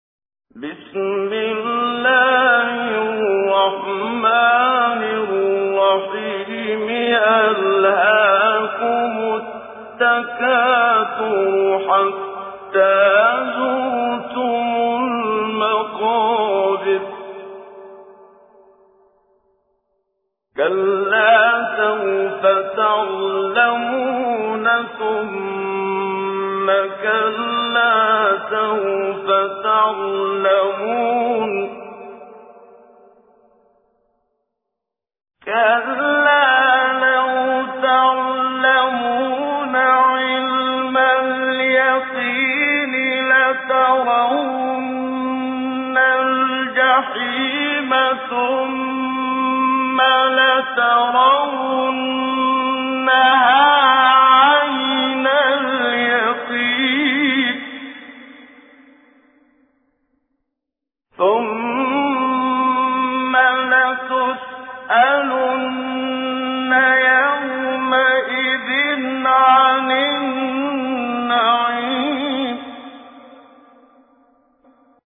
تجويد
سورة التكاثر الخطیب: المقريء الشيخ محمد صديق المنشاوي المدة الزمنية: 00:00:00